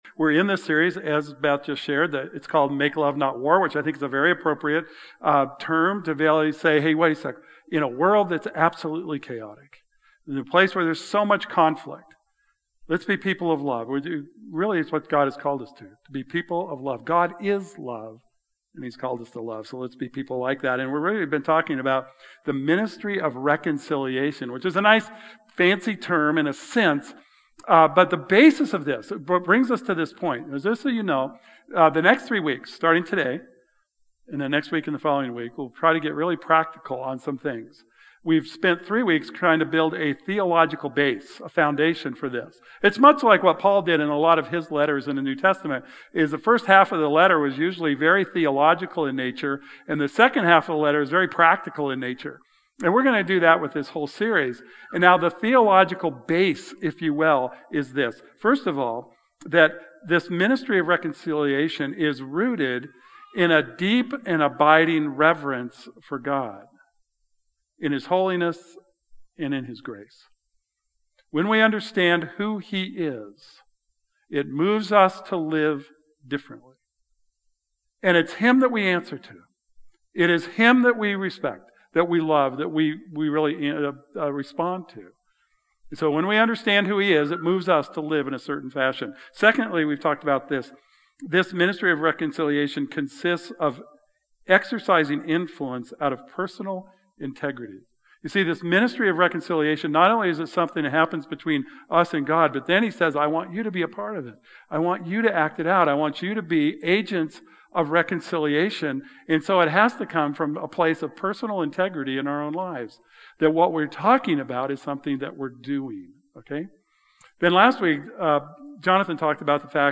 This morning's message focuses on the area of reconciliation in marriage and gives some useful and tangible ways of taking steps toward a healthier relationship or marriage.